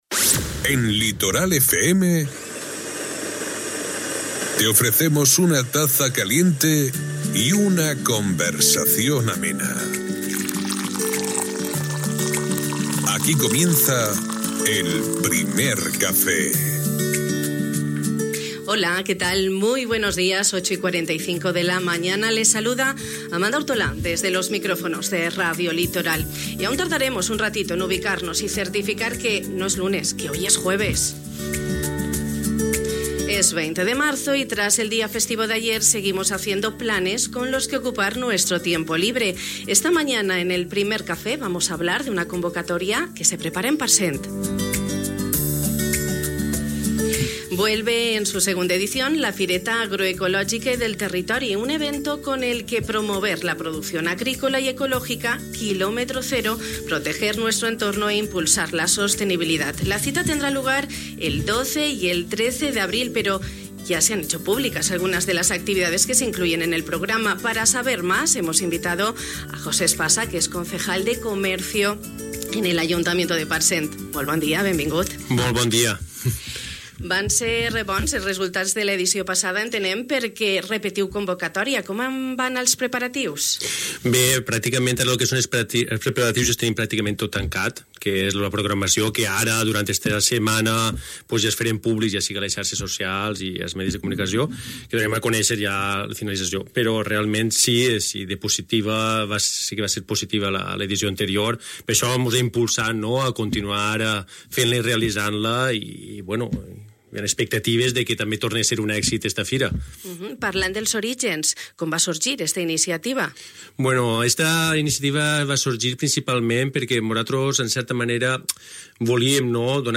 La cita s'ha fixat per al 12 i 13 d'abril, i aquest matí, amb José Espasa, regidor de Comerç, hem pogut conèixer les activitats que s'inclouen al programa.